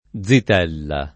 zitella [+Zit$lla o